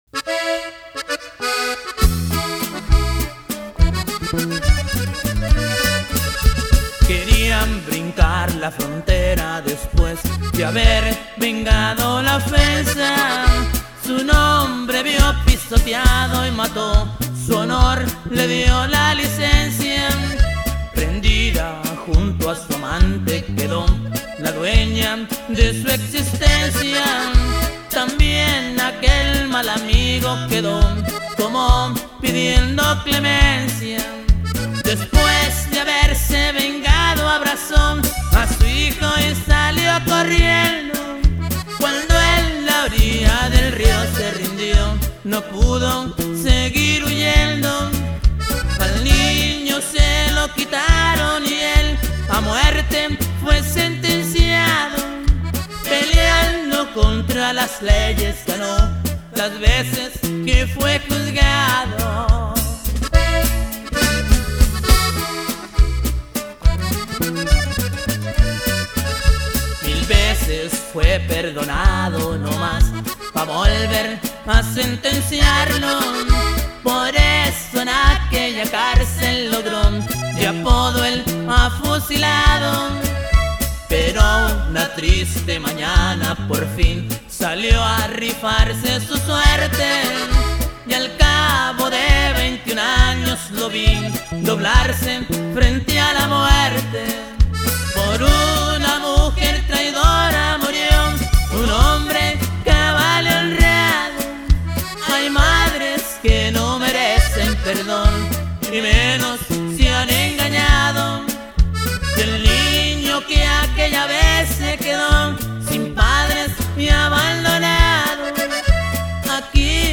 toco el bajo sexto y primera vos
1er acordion y teclados y segunda vos
bajo electrico.
segundo acordion y segunda vos.
pero el dise que es de Durango y toca la bateria.